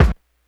kick05.wav